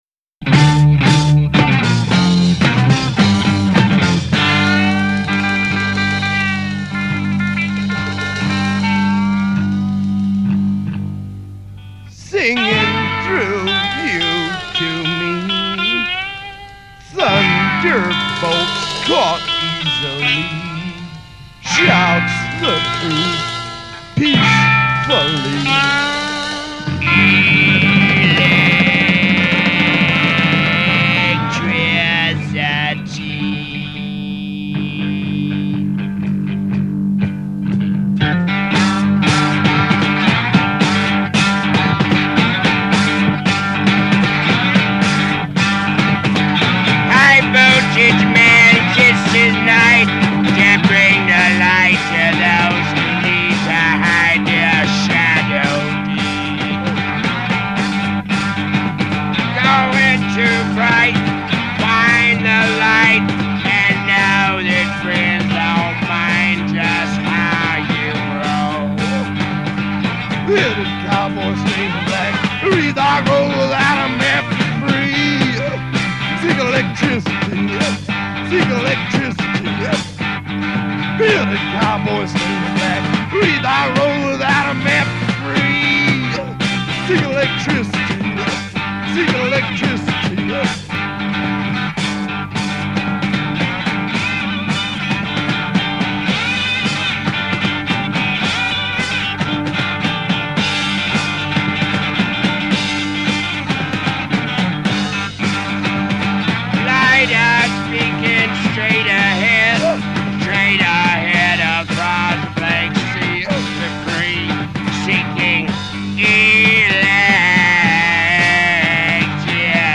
(1968 live)